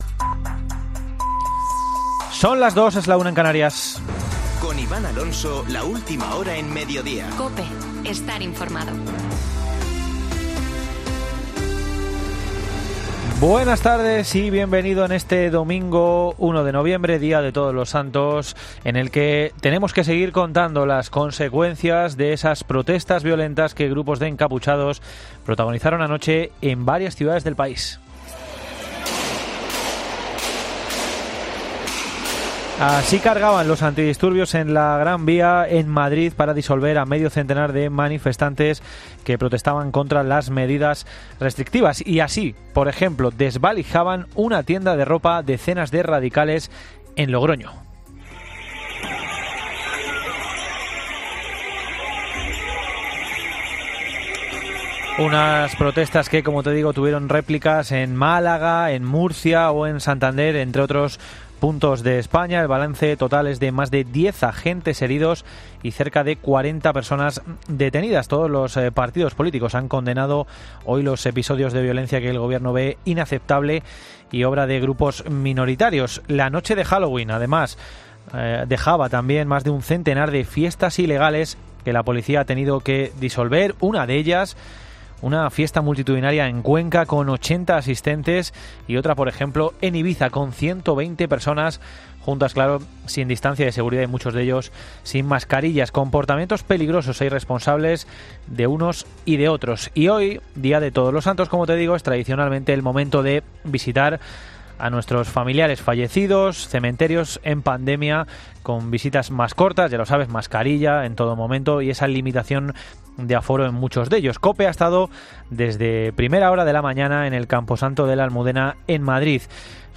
Boletín de noticias de COPE del 1 de noviembre de 2020 a las 14.00 horas